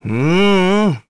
Neraxis-Vox-Deny1_kr.wav